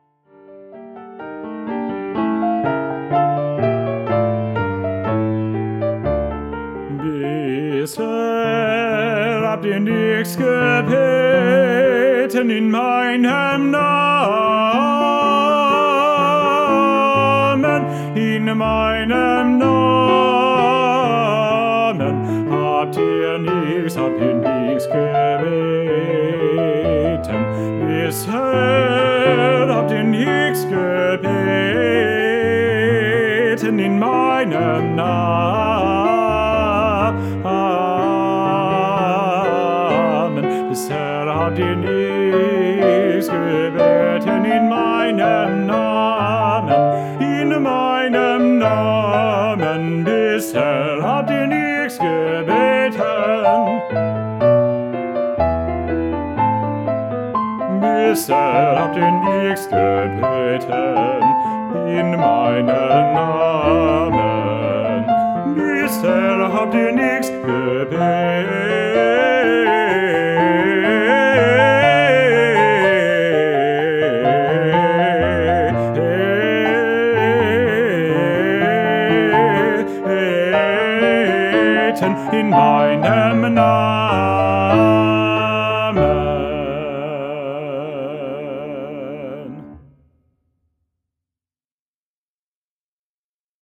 Solo Voice
Classical:
Voice
Piano